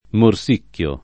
vai all'elenco alfabetico delle voci ingrandisci il carattere 100% rimpicciolisci il carattere stampa invia tramite posta elettronica codividi su Facebook morsicchiare v.; morsicchio [ mor S& kk L o ], ‑chi — ant. morsecchiare : morsecchio [ mor S% kk L o ], ‑chi